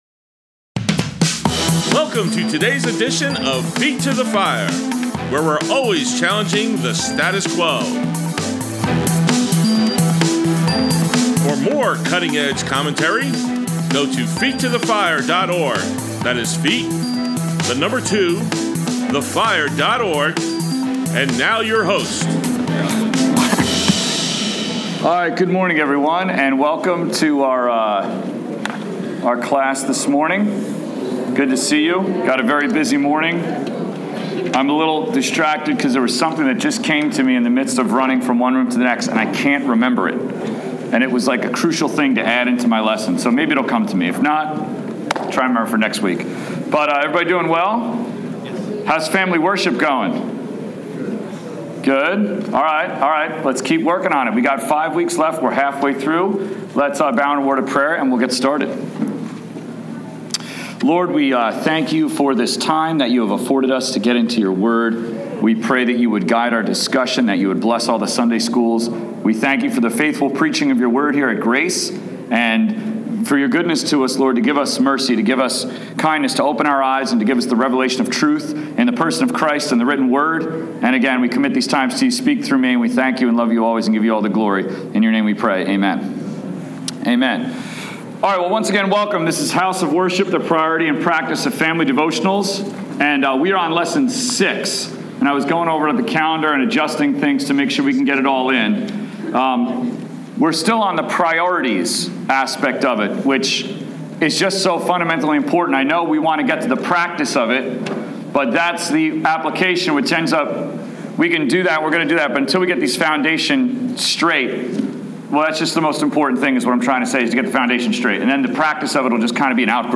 Grace Bible Church, Adult Sunday School, 2/21/16